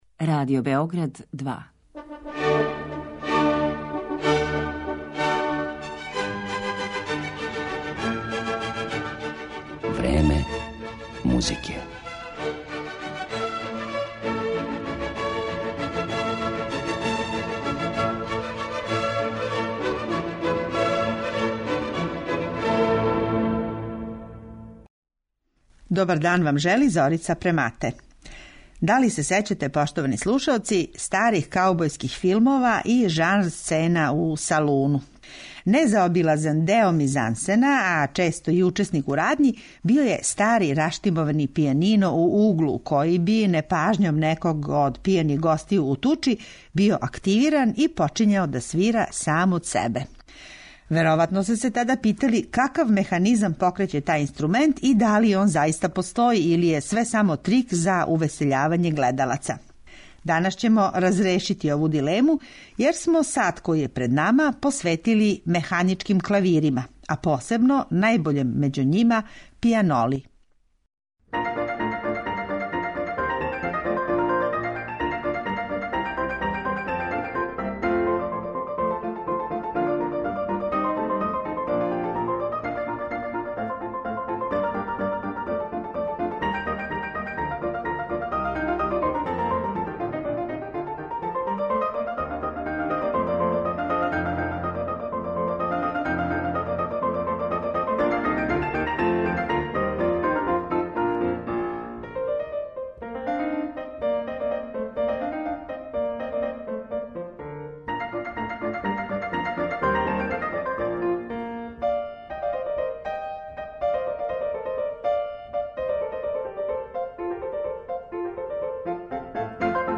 Механички инструмент - пијанола